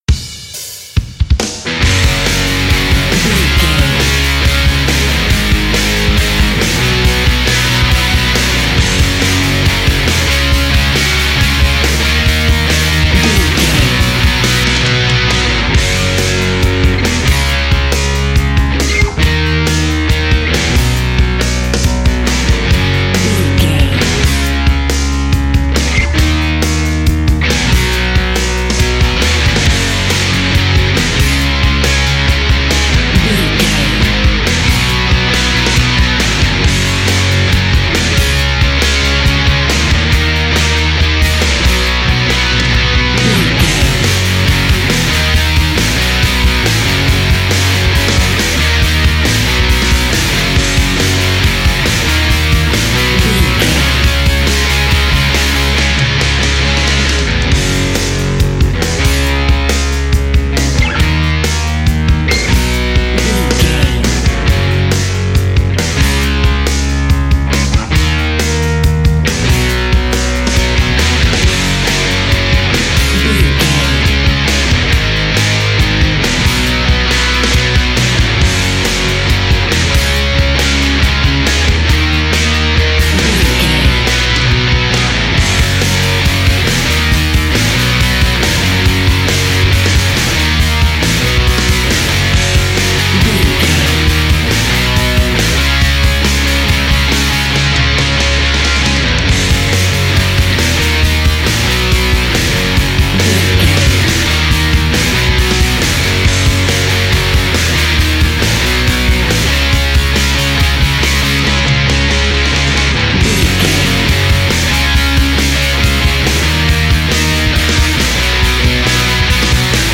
Ionian/Major
F#
drums
electric guitar
bass guitar
Sports Rock
hard rock
lead guitar
aggressive
energetic
intense
powerful
nu metal
alternative metal